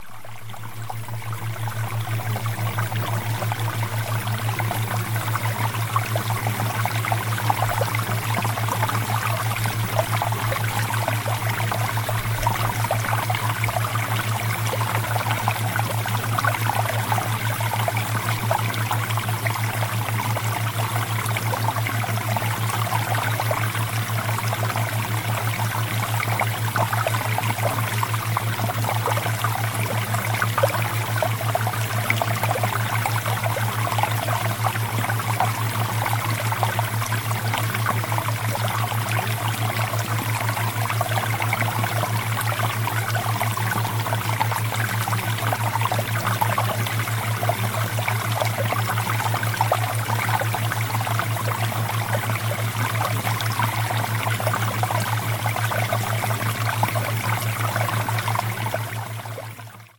MALE-ENHANCEMENT-Water-Theta-Waves-Sample.mp3